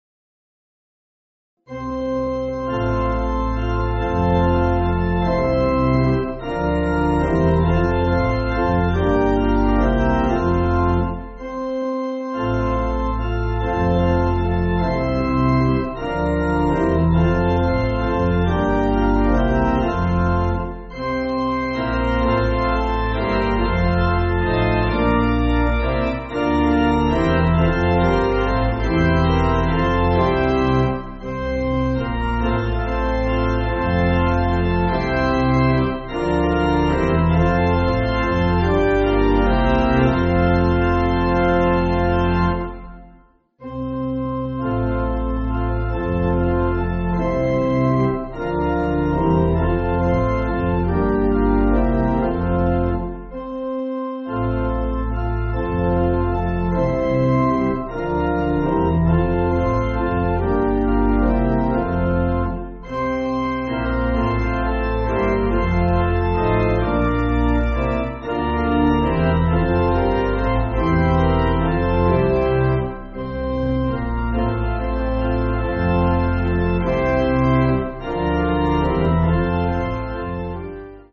8.8.8.8.D
Organ